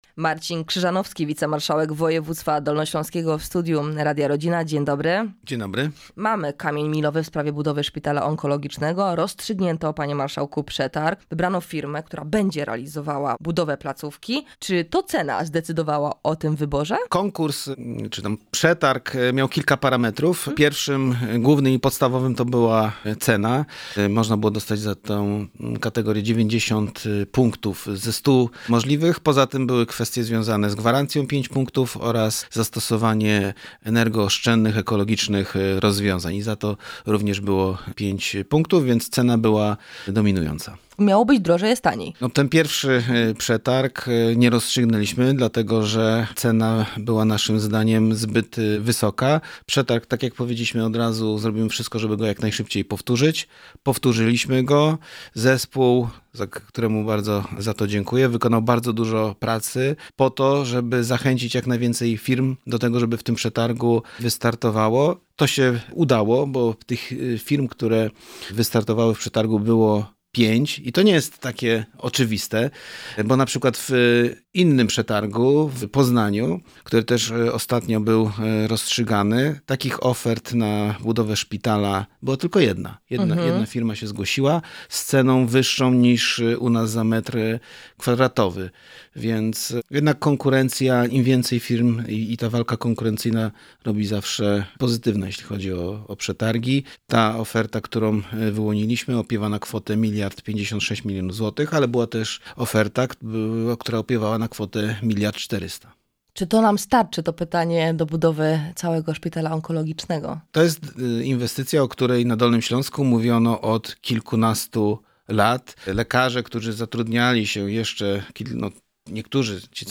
Plan budynku oparty jest na kształcie litery Y, uwzględnia ścieżkę pacjenta tak, aby miał on możliwość jak najszybszego dostania się do lekarza, laboratorium czy na oddział. Mówi Marcin Krzyżanowski – Wicemarszałek Województwa Dolnośląskiego.